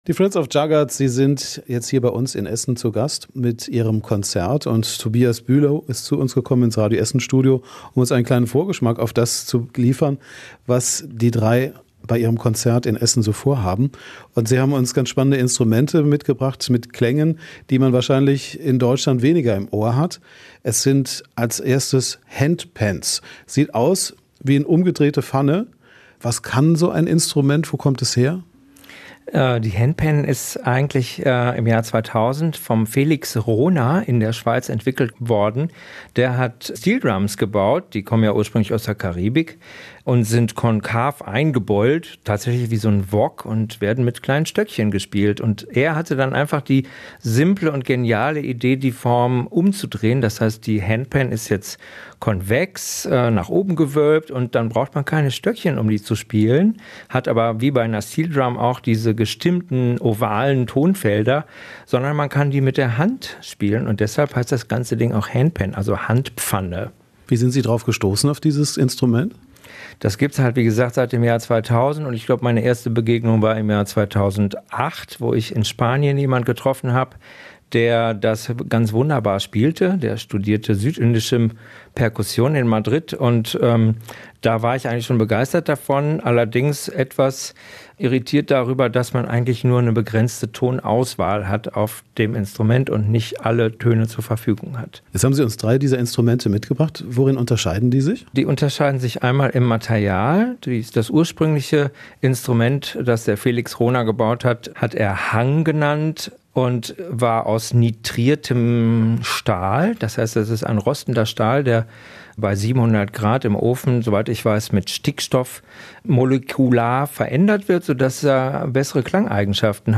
Zu hören sind unter anderem orientalische Saiteninstrumente und indische Flöten.